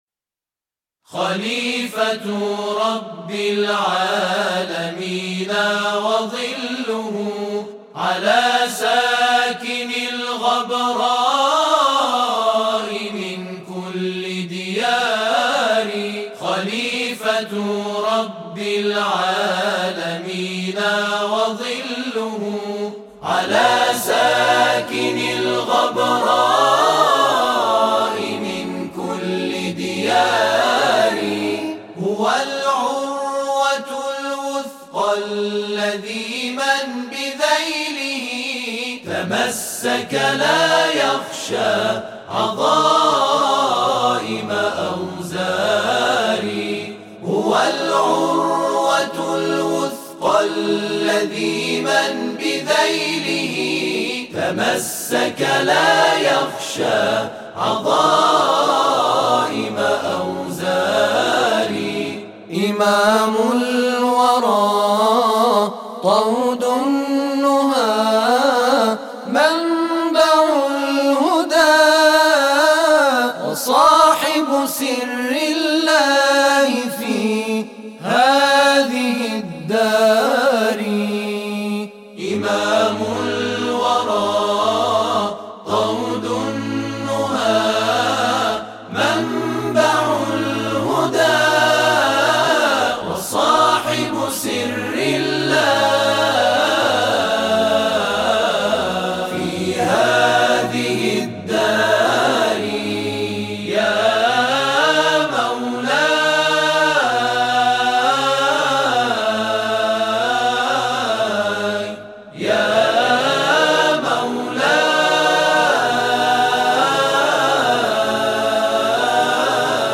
گروه تواشیح حضرت معصومه